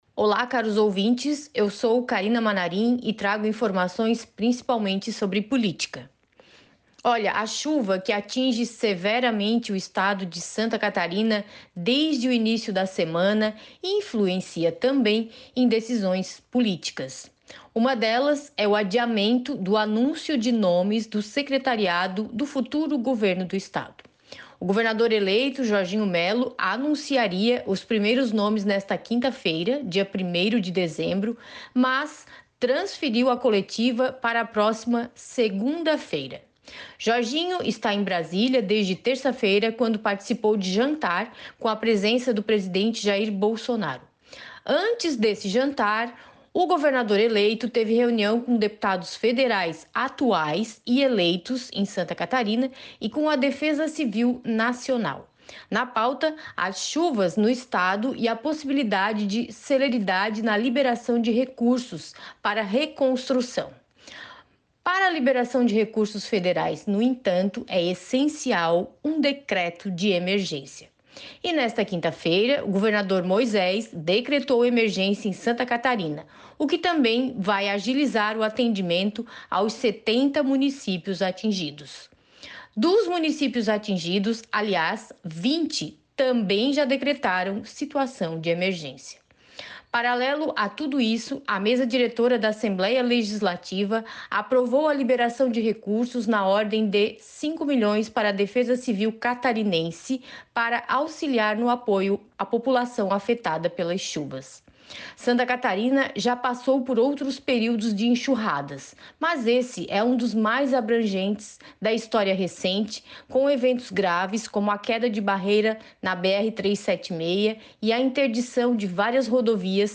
Jornalista também destaca as fortes chuvas que assolam o Estado; segundo ela, a Defesa Civil Nacional deve trazer recursos para o Estado